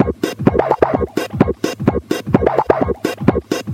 VEH1 Fx Loops 128 BPM
VEH1 FX Loop - 14.wav